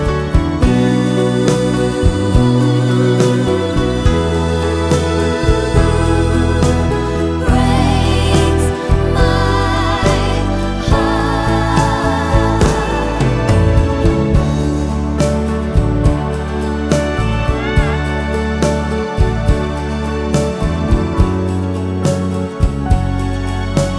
No Backing Vocals Crooners 3:11 Buy £1.50